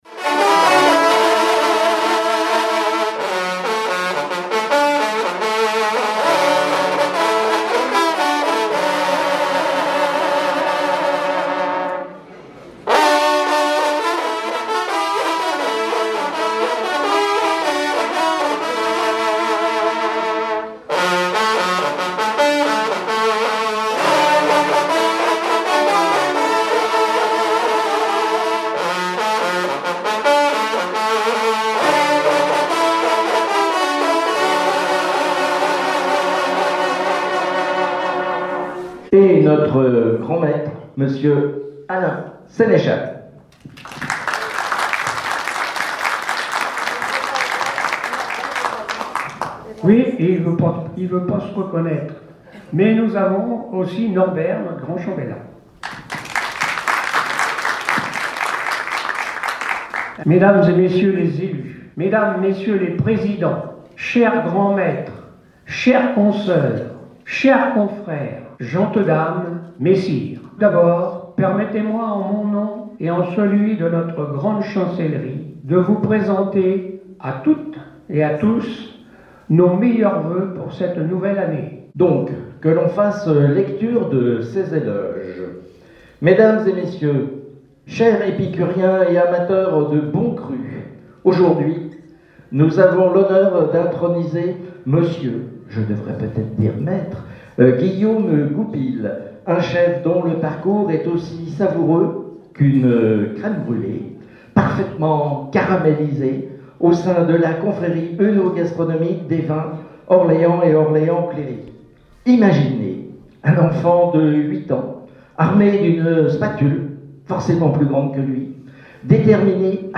VAG MUSIC -interview confréries no 1